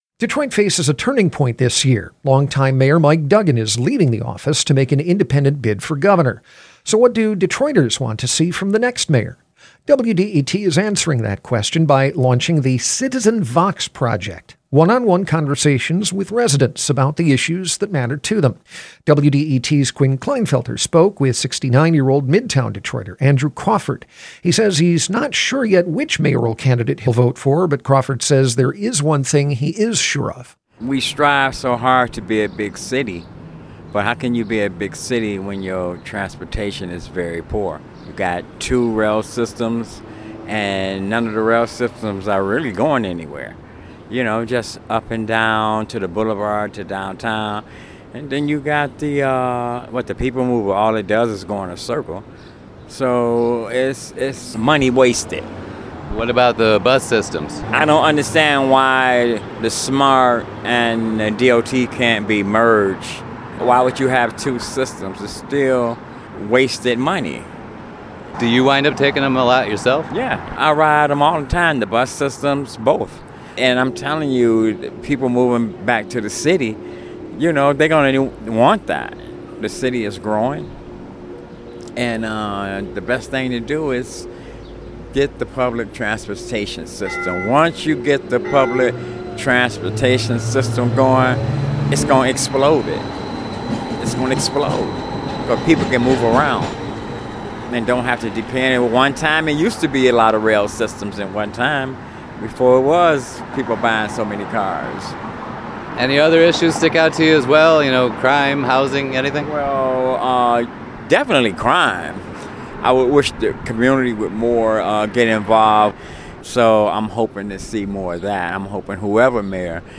These are one-on-one conversations with Detroit residents about the issues that matter to them.
The following interview has been edited for clarity and length.